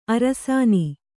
♪ arasāni